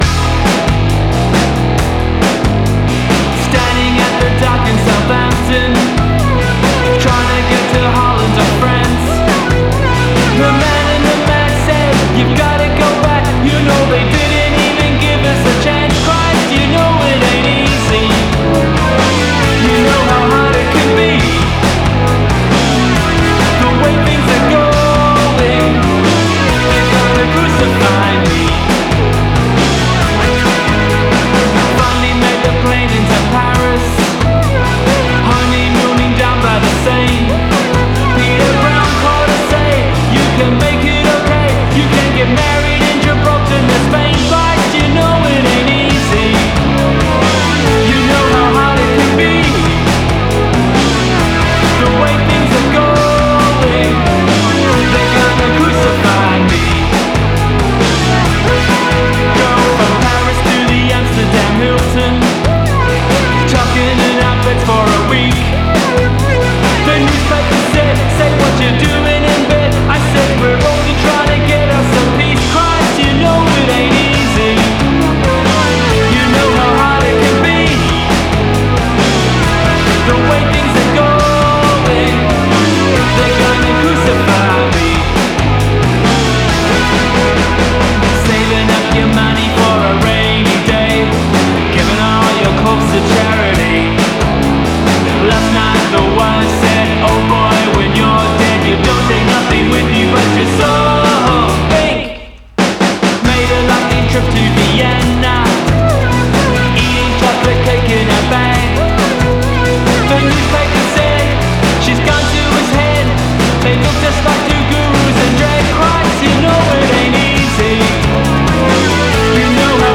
Genres : alt-rock, grunge, industrial, lo-fi, rock, synth